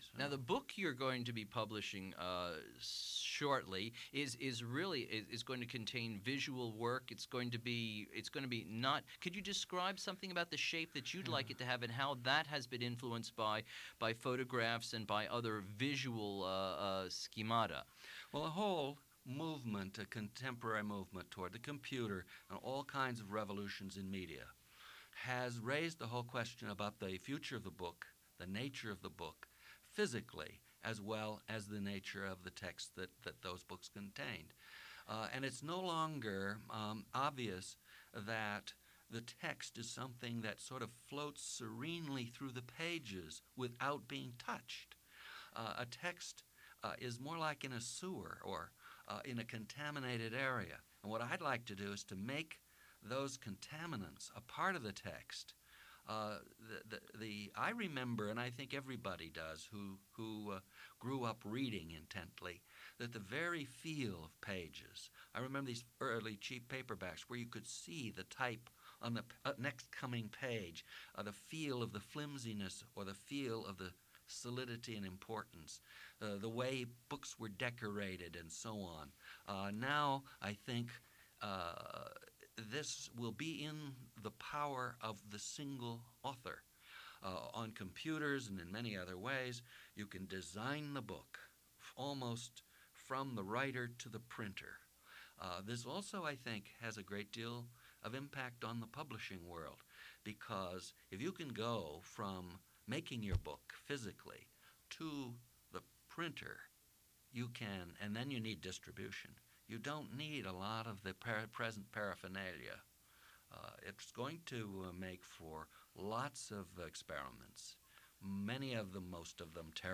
This radio interview was done before Gass spoke at the Lannan Foundation Literary Reading Series on January 21, 1992, when he was a visiting scholar at the Getty Institute in Los Angeles.
Audio Cassette